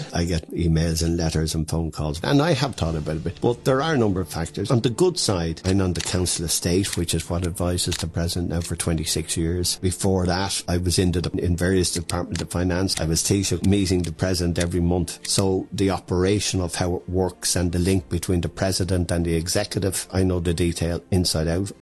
Speaking to his son in law on the Nicky Byrne HQ podcast, he says there is no one else around with his level of experience: